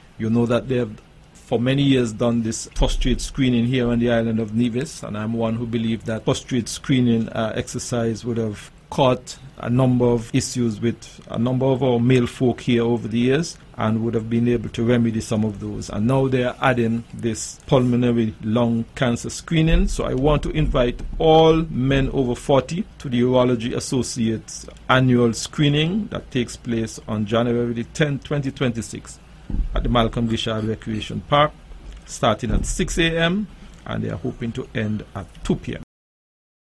This is the voice of the Hon. Spencer Brand: